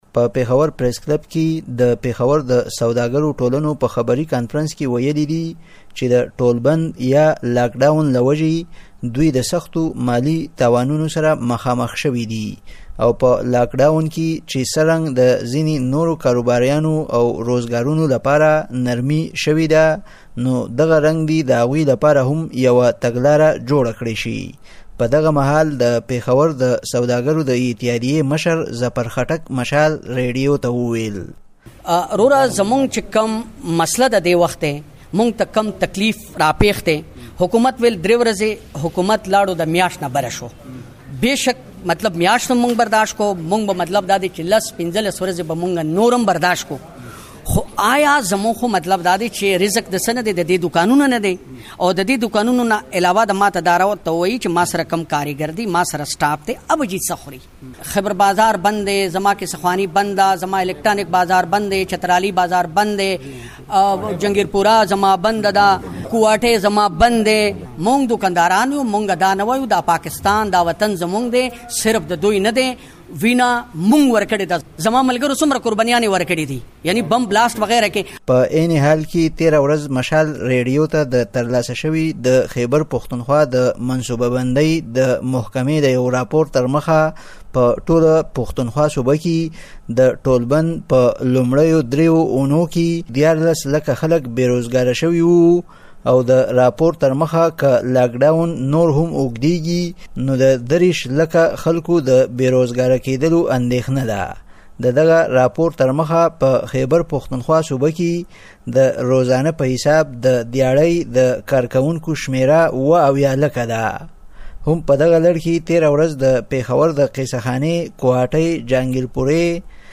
په پېښور پریس کلب کې د اپرېل پر۲۷مه د پېښور د سوداګرو ټولنو په خبري کانفرنس کي ویلي دي چې د ټولبند له وجې له سختو مالي تاوانونو سره مخامخ شوي دي او د هغوی لپارهدې د ټولبند نرمولو لپاره یوه تګلاره جوړه کړل شي.